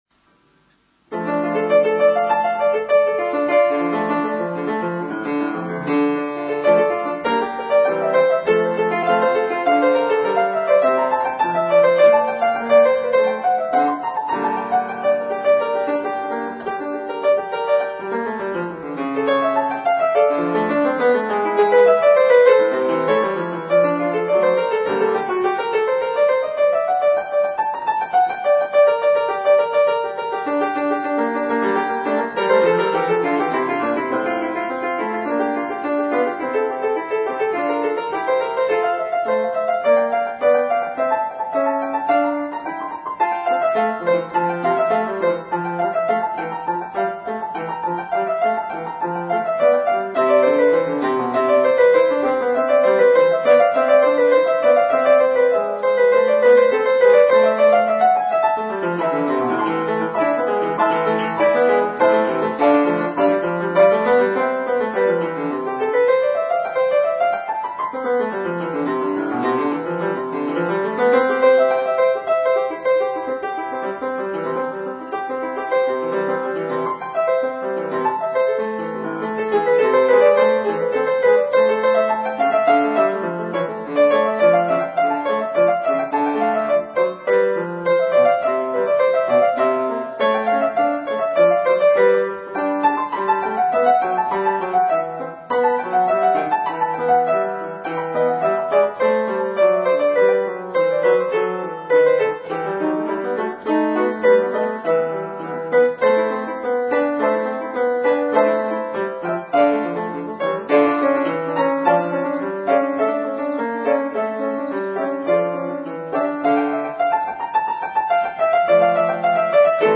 Early keyboard music,harpsichord,piano